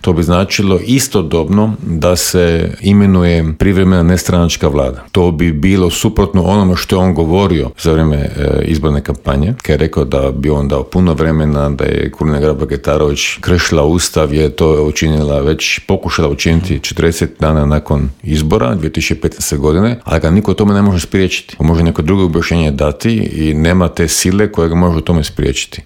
O izlaznosti, rezultatima i pregovorima za formiranje vladajuće većine razgovarali smo s HDZ-ovcem Mirom Kovačem.